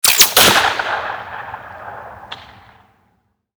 Railgun_Far_01.ogg